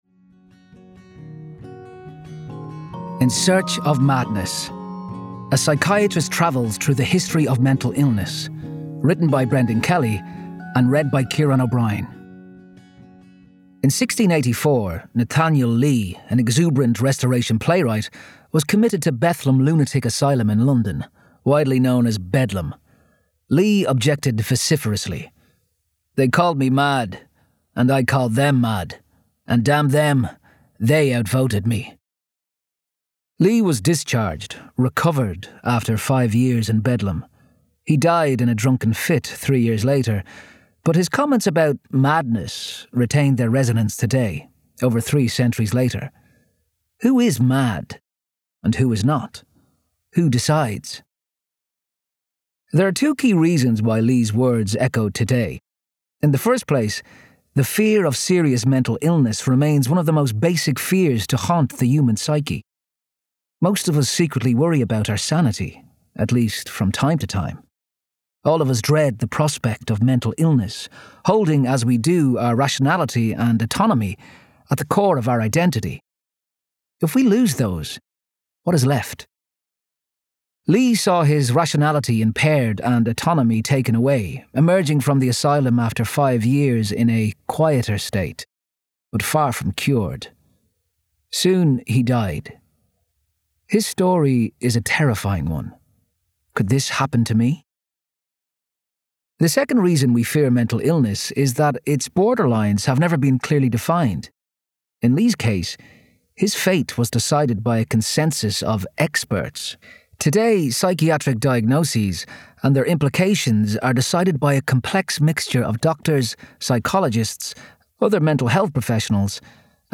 Narration - In Search of Madness